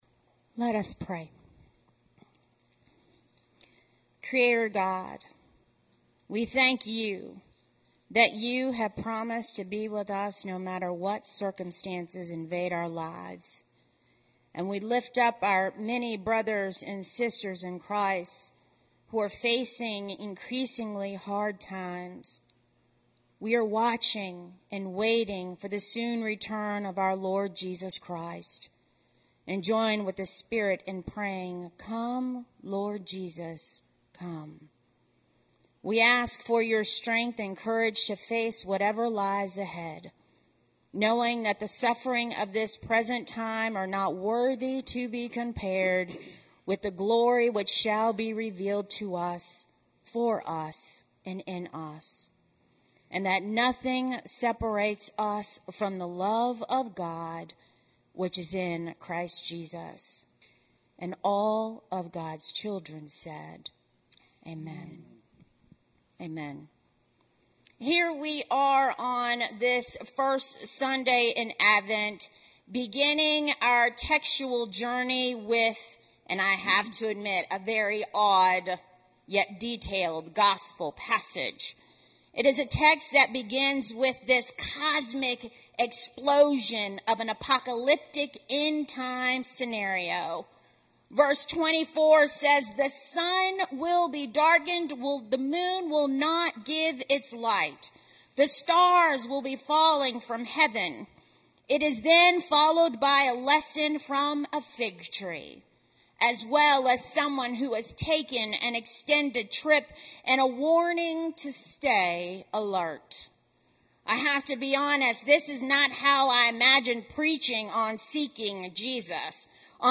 A message from the series "Seeking Jesus."